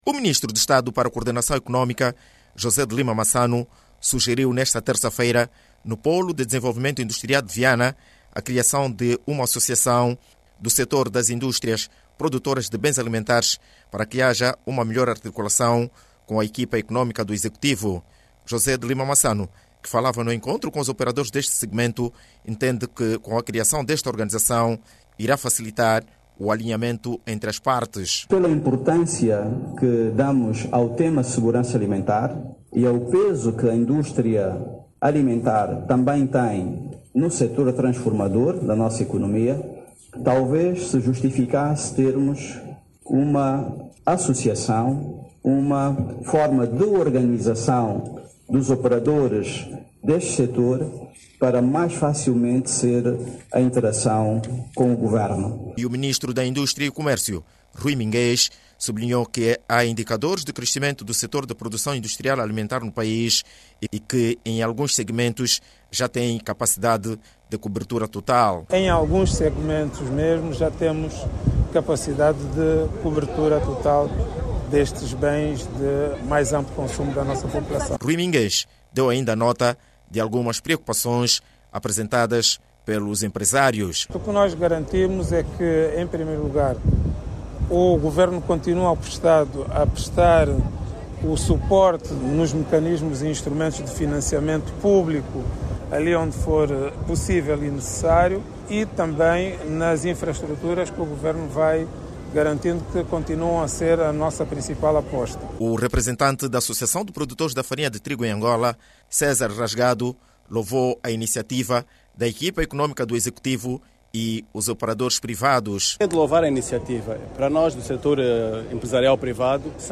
O Ministro de Estado para Coordenação Económica, José de Lima Massano sugere a criação de uma associação do Sector Produtor de Bens alimentares. José de Lima Massano, fez este pronunciamento durante a visita ao polo industrial de Viana, tende afirmado que a medida visa melhorar a articulação com a equipa económica do executivo. Clique no áudio abaixo e ouça a reportagem